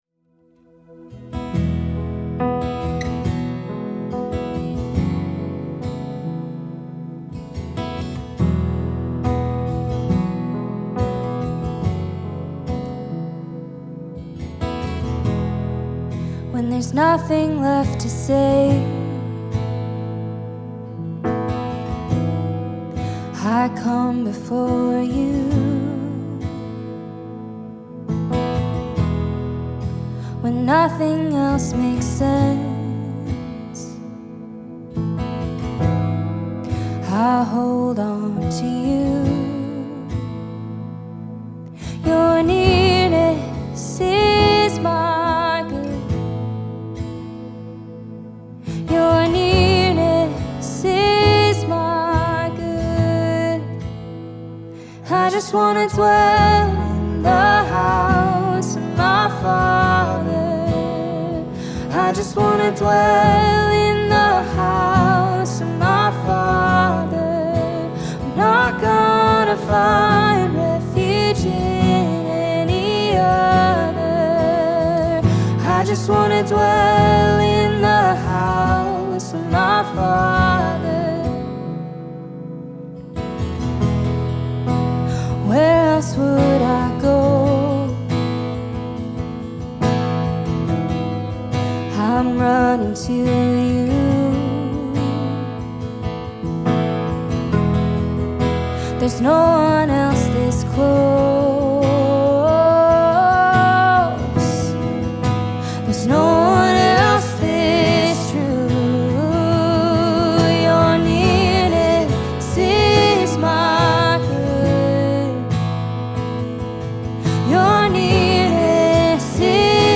Jesus Worship